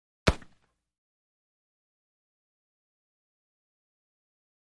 Musket Fire
Black Powder Musket 1; Single Shot.